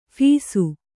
♪ phīsu